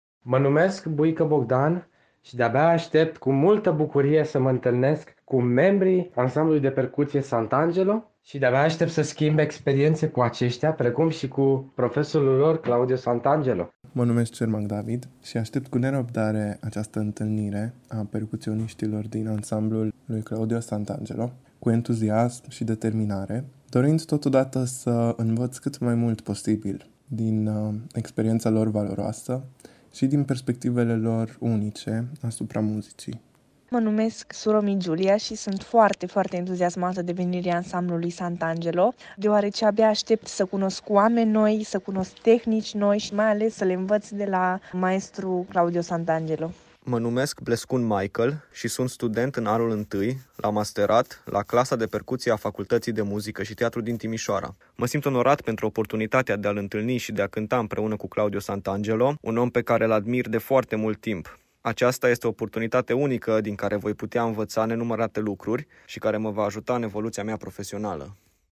EXCLUSIVITATE: membrii Ansamblului PERCUTISSIMO